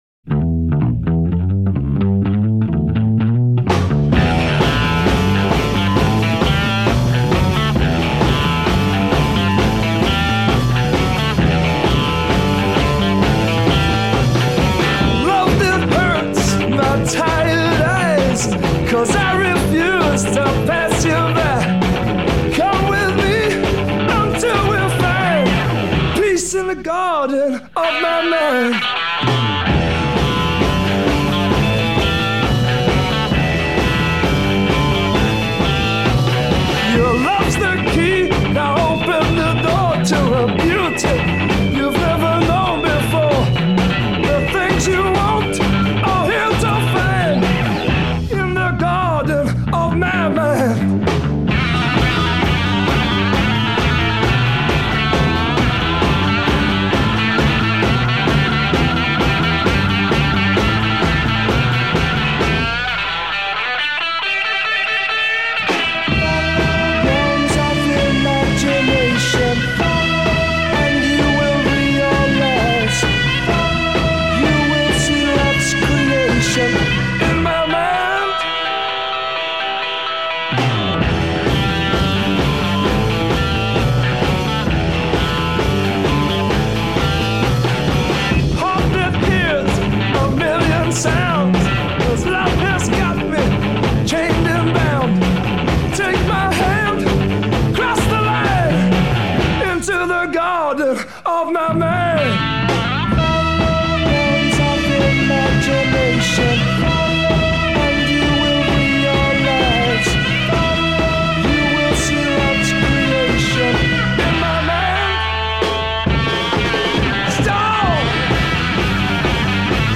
Freakbeat Movement
pure Psychedelia